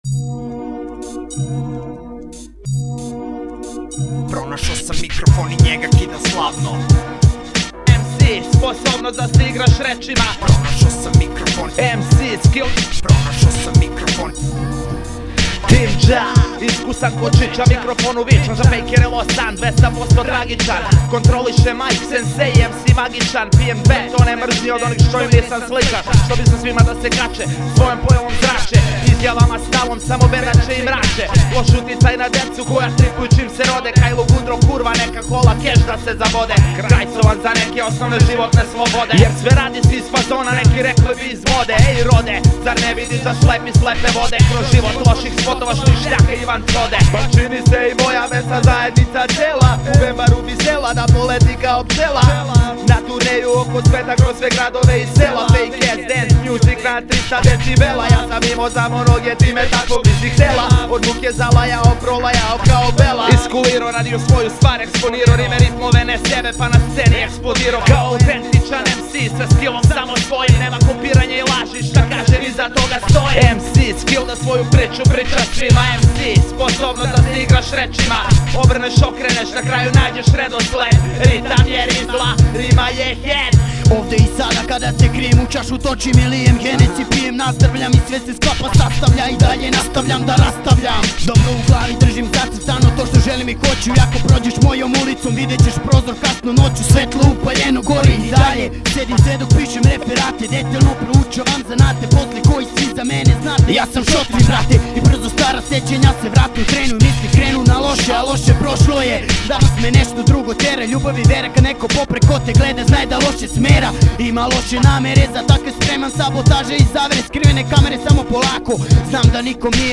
muzej (ne)objavljenih pesama domaćeg repa